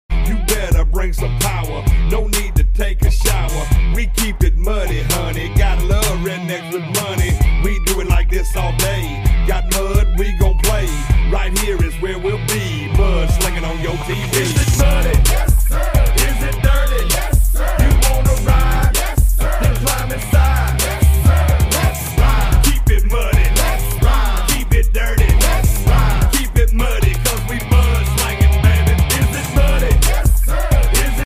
Revving up for an adrenaline fueled sound effects free download